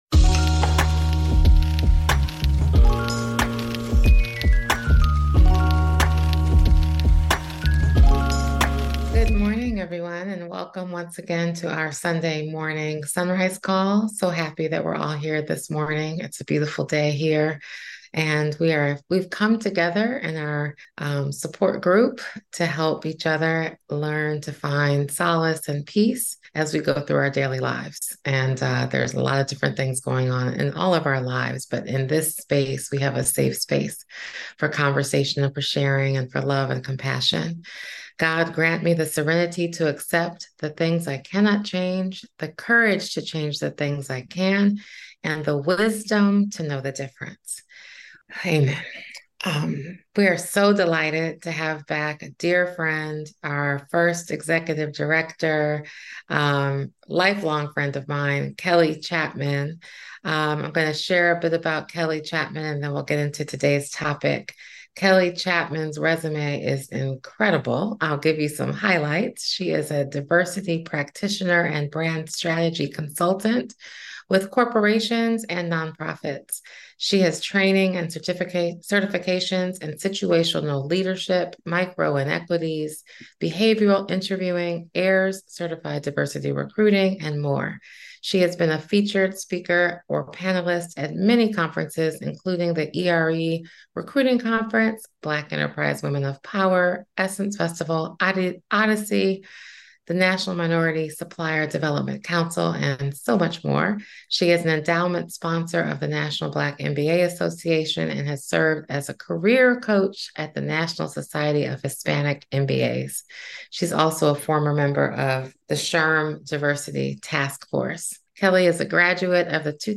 for a special SonRise Conversation.